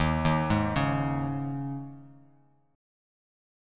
D#7sus4 chord